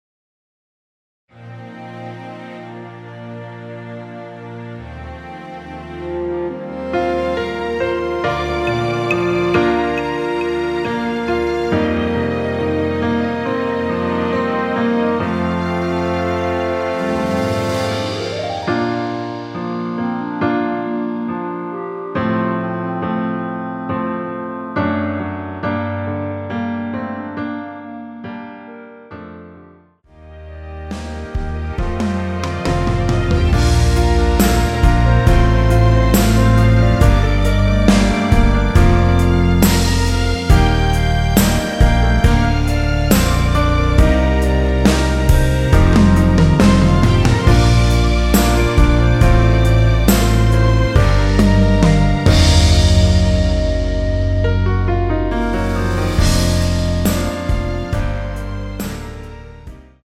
원키에서(-3)내린 멜로디 포함된 MR입니다.
Bb
앞부분30초, 뒷부분30초씩 편집해서 올려 드리고 있습니다.
중간에 음이 끈어지고 다시 나오는 이유는